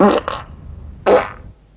文件夹里的屁 " 屁03
描述：从freesound上下载CC0，切片，重采样到44khZ，16位，单声道，文件中没有大块信息。准备使用！在1个文件夹中有47个屁;）
Tag: 喜剧 放屁 效果 SFX soundfx 声音